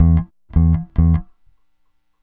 Retro Funkish Bass Ending.wav